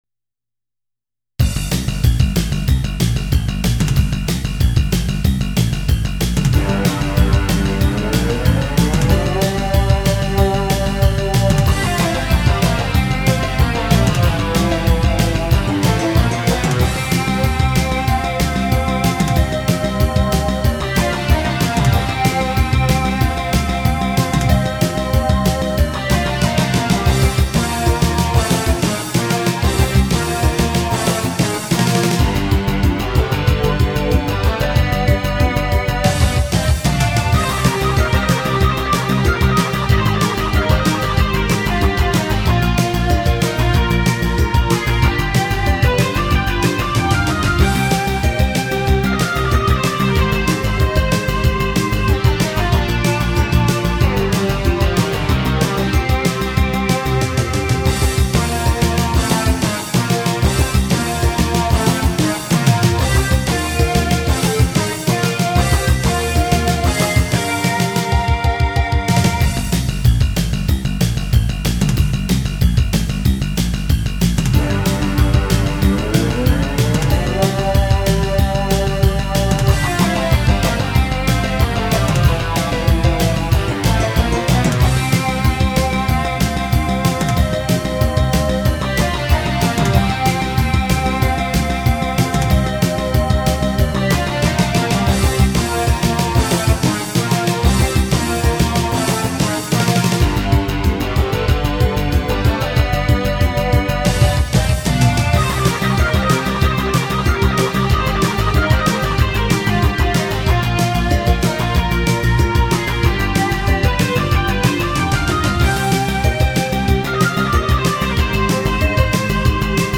過去に制作したGM版から好きな曲をSC-88Pro互換MAPで作り直しました。
GM版と比べて贅沢な構成にした為か、ドラムがモタってしまいました。